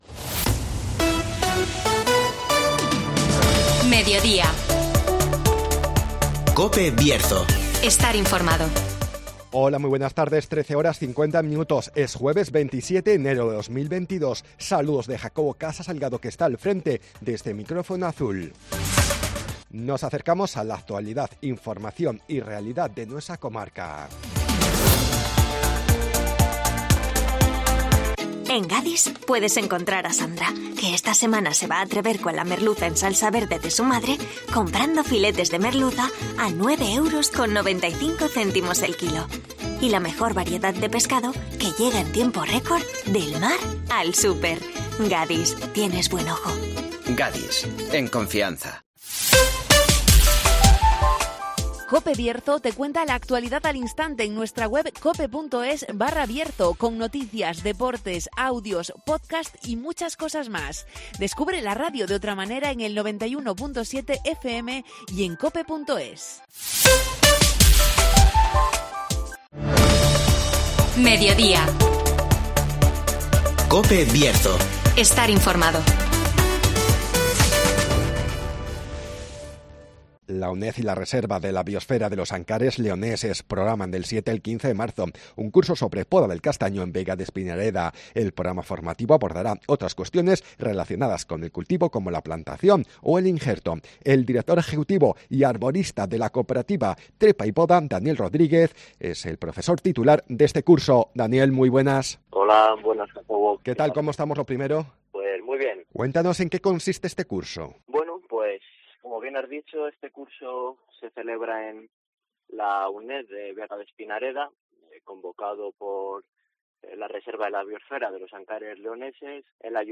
La Uned y la Rbale programan del 7 al 15 de marzo un curso sobre poda del castaño en Vega de Espinareda (Entrevista